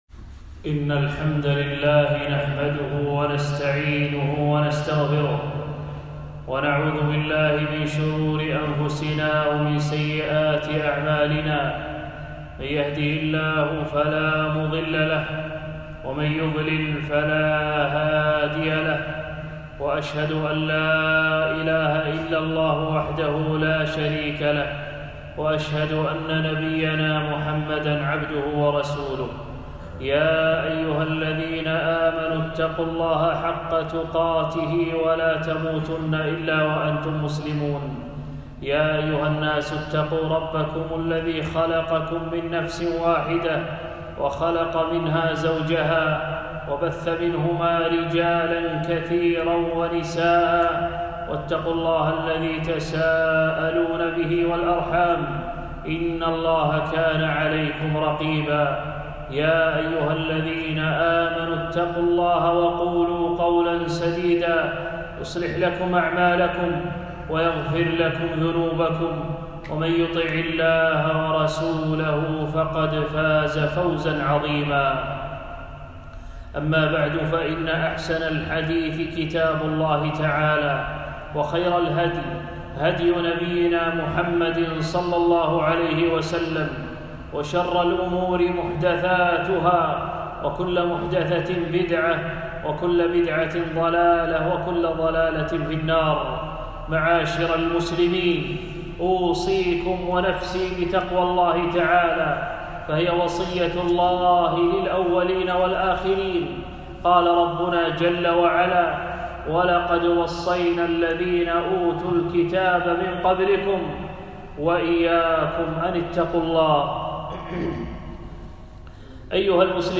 خطبة - الإيمان بالملائكة